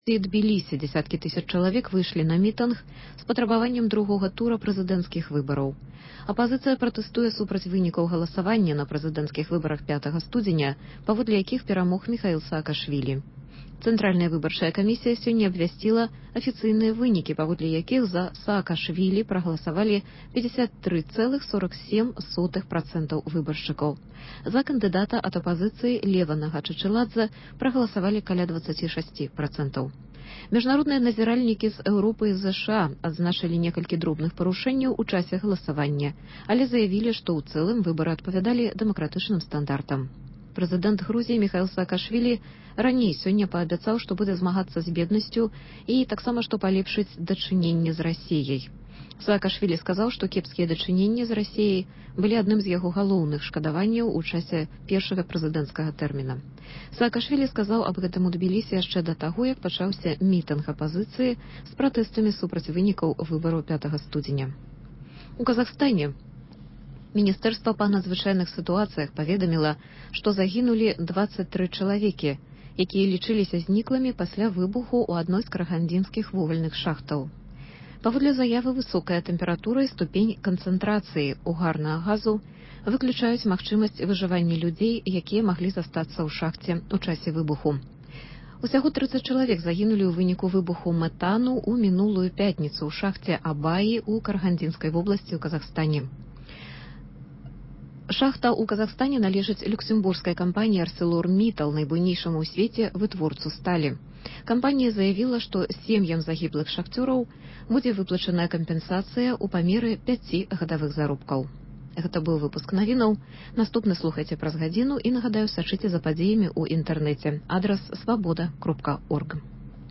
Ці сапраўды 760 ўказ пазбаўляе іх магчымасьці працягваць весьці свой бізнэс? Наколькі заканамерным зьяўляецца зьмяншэньне долі дробнага бізнэсу па меры разьвіцьця рынкавай эканомікі? Гэтыя пытаньні ў “Праскім акцэнце” абмяркоўваюць: